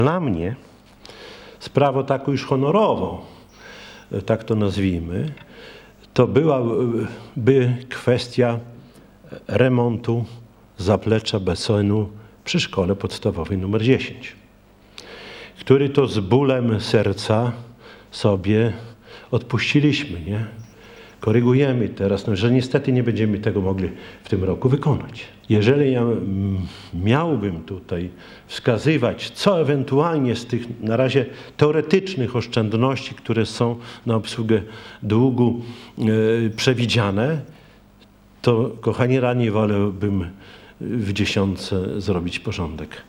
W odpowiedzi na wniosek radnego Czesław Renkiewicz, prezydent miasta przyznał, że zgadza się z postulatami radnego, ale za punkt honoru wziął kwestię remontu zaplecza basenu w Szkole Podstawowej numer 10.